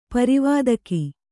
♪ parivādaki